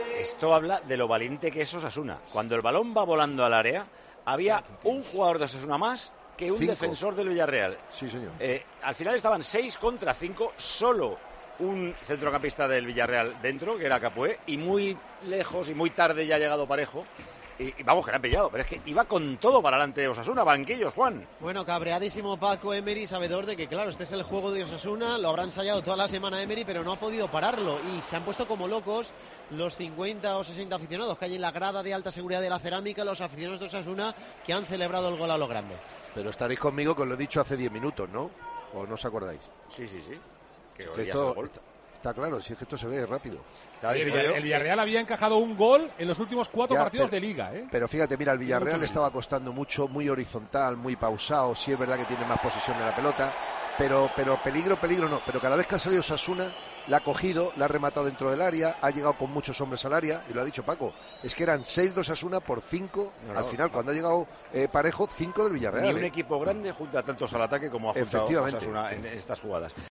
Elogios del director de Tiempo de Juego a la valentía del equipo de Jagoba Arrasate en el triunfo de Osasuna en Villarreal
Paco González habla maravillas del Osasuna de Jagoba Arrasate